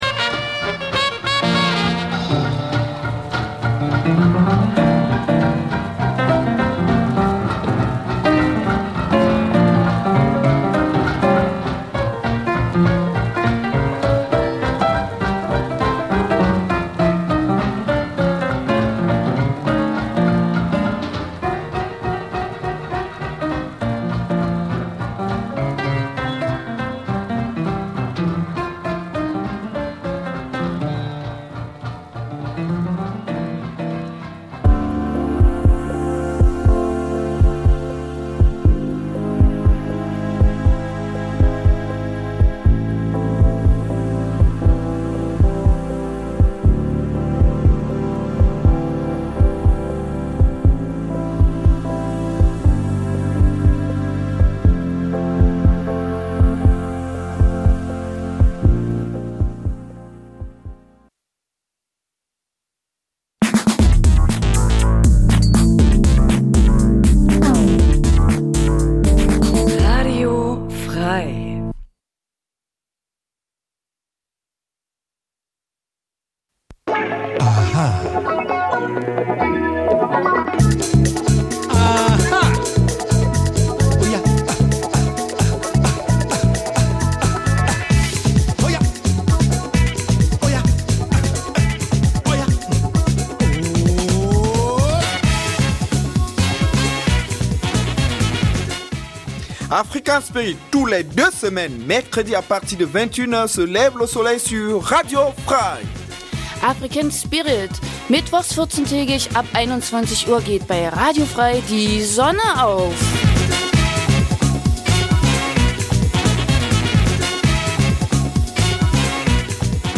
Speziell versuchen wir die Berührungspunkte zwischen Afrika und Thüringen zu beleuchten. In einem Nachrichtenblock informiert die Sendung über die aktuelle politische Situation vor allem aus Afrikas Krisengebieten, aber auch über die Entwicklung der Zuwanderungs-politik in Deutschland welche das Leben vieler Afrikaner bei uns beeinflußt. Neben aktuellen Nachrichten gibt es regelmäßig Studiogäste, Menschen die in irgendeiner Form etwas mit Afrika zu tun ha
Die Gespräche werden mit afrikanischer Musik begleitet.